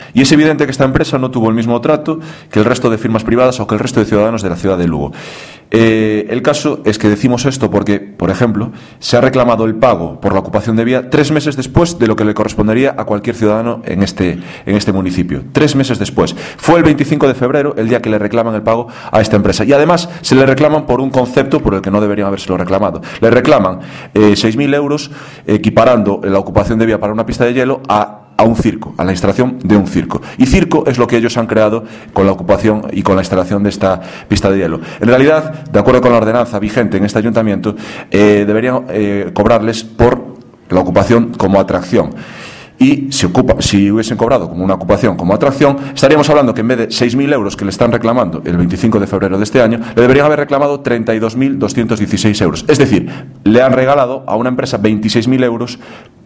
O vicevoceiro do Grupo Municipal do Partido Popular no Concello de Lugo, Antonio Ameijide, acusou esta maña en rolda de prensa ao goberno municipal de regalar 30.000 euros á empresa da pista de xeo.